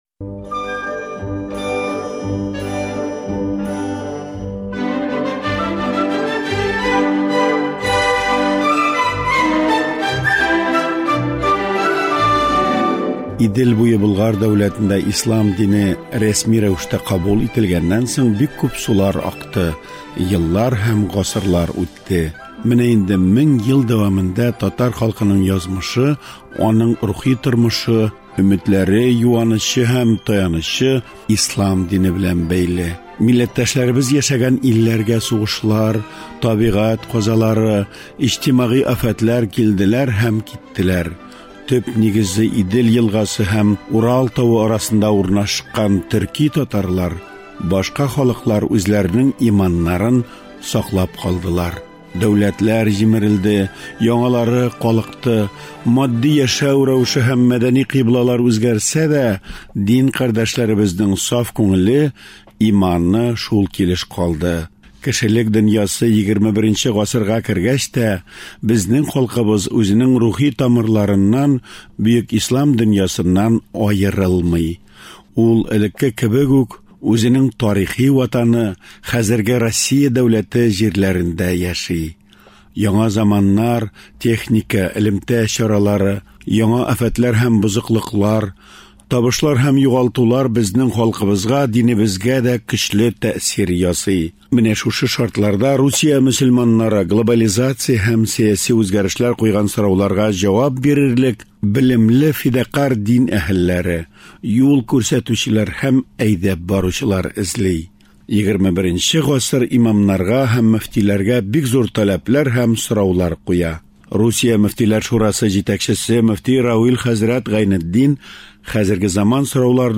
Равил Гайнетдин белән әңгәмә